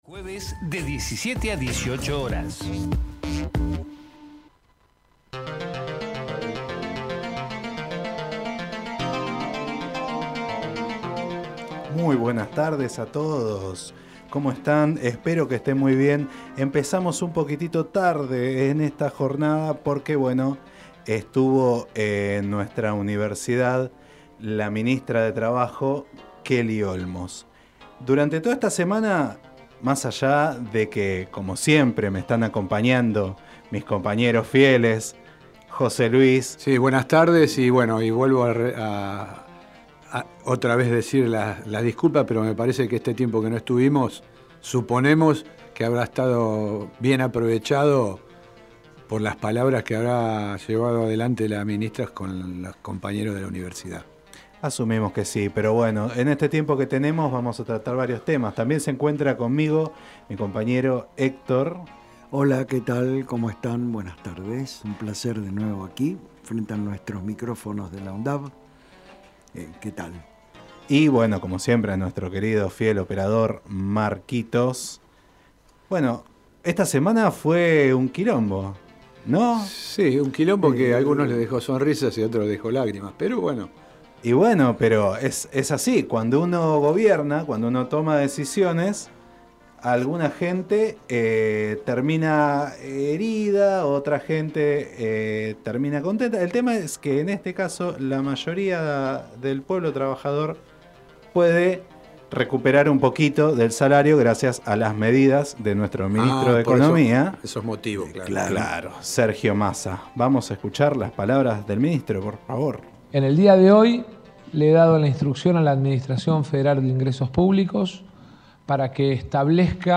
Música, humor y política.